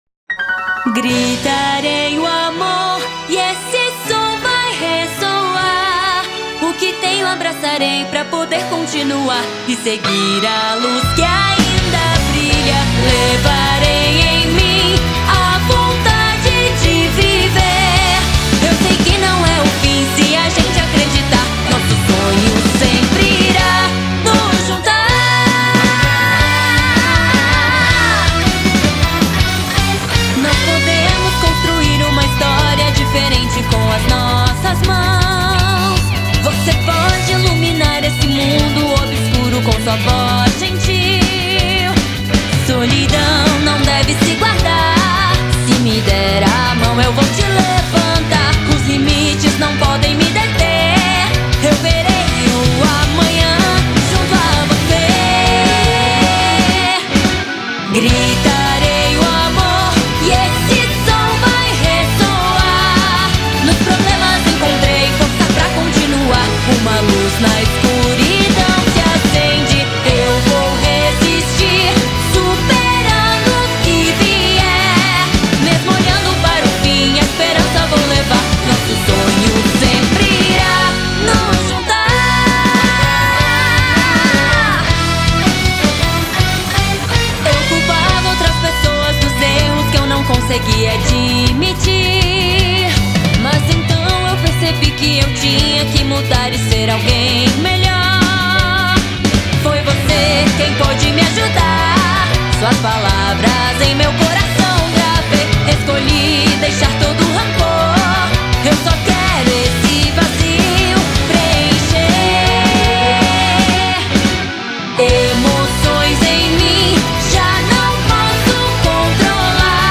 2025-02-23 16:01:54 Gênero: Rap Views